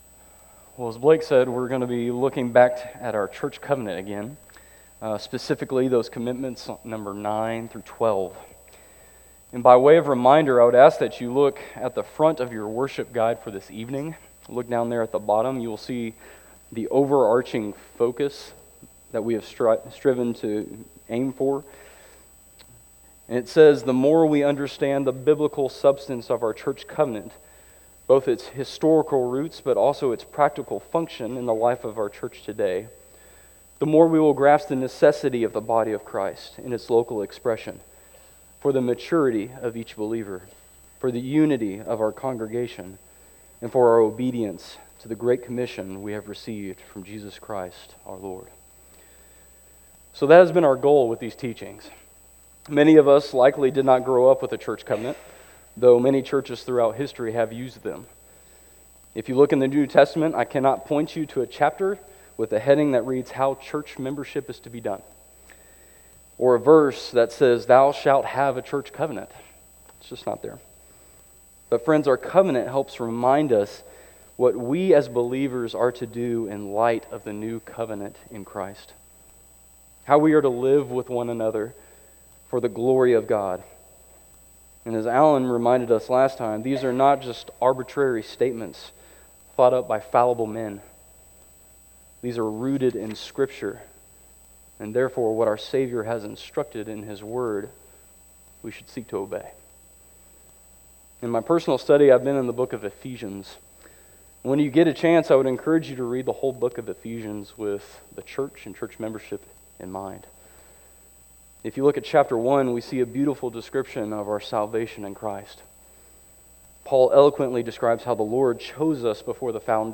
This message was delivered on Sunday evening, March 1, 2026, at Chaffee Crossing Baptist Church in Barling, AR.